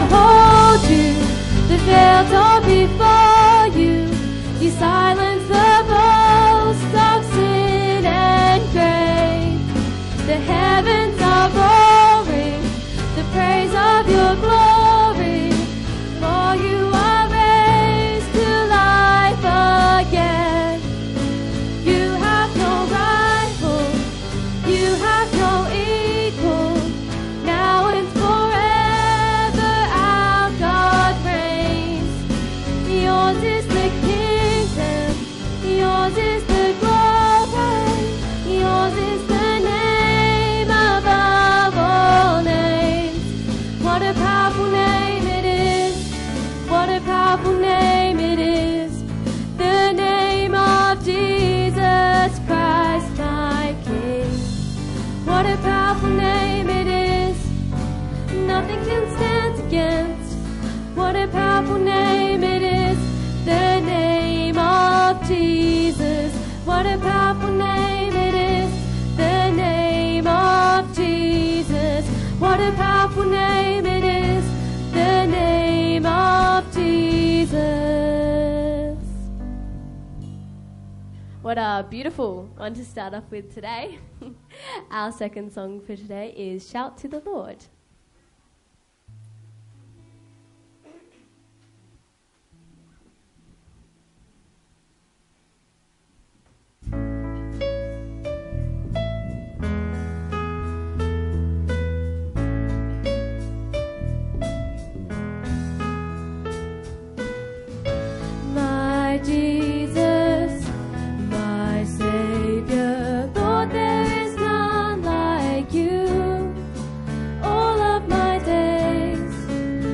Service Type: Sunday Church Download Files Notes Topics: Baptism , Baptism of Jesus , Christ , The Holy Ghost « 2026 New Year Mission